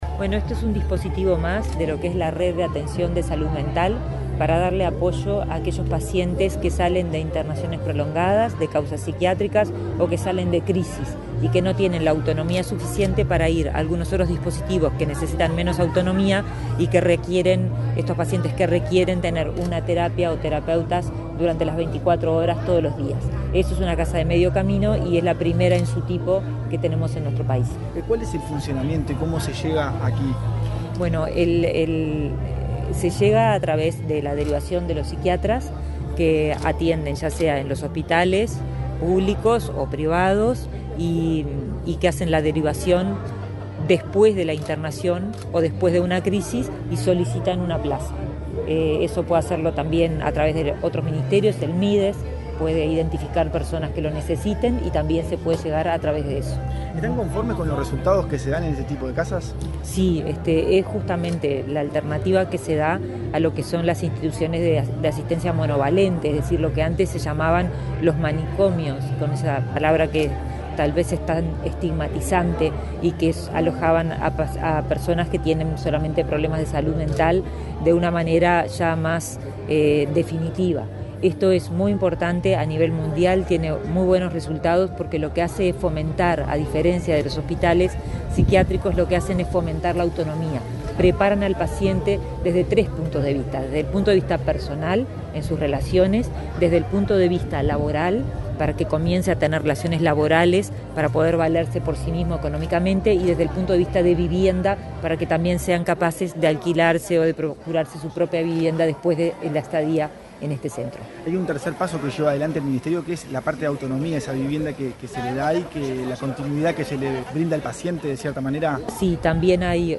Declaraciones de la ministra de Salud Pública, Karina Rando
Luego, la titular del MSP dialogó con la prensa.